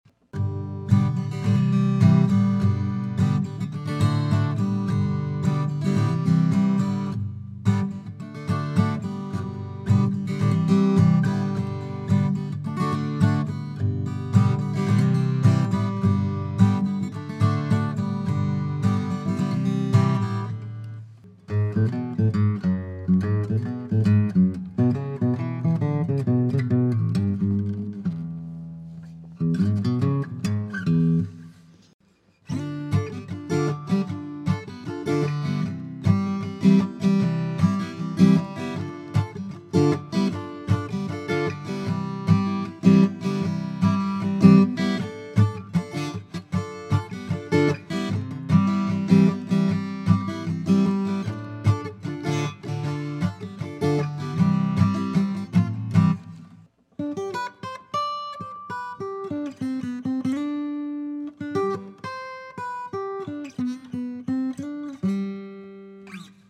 Curly walnut 00 #23029 Here’s a beautiful small bodied guitar featuring a gorgeous curly walnut body paired with a AAA grade Engelmann spruce top that has wonderful cross grain silking.
This 00 sized beauty feels extremely comfortable in your hands, plays like butter, and sounds even better than it looks.